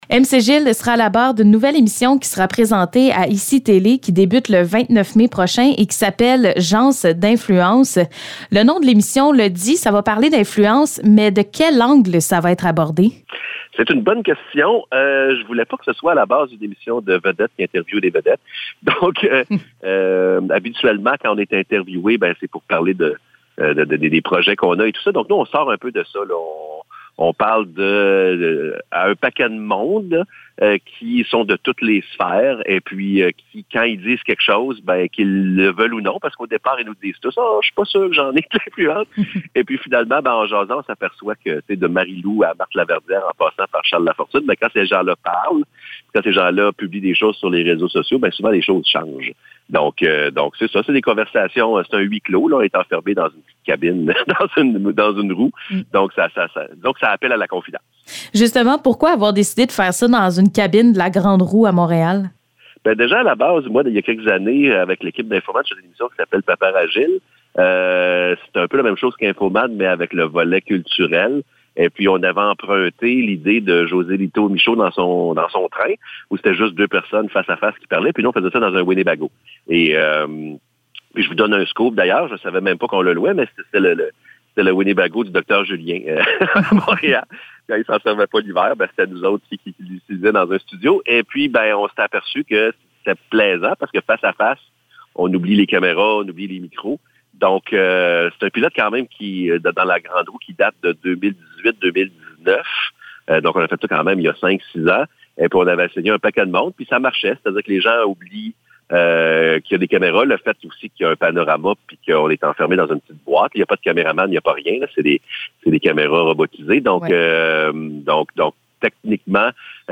Entrevue avec MC Gilles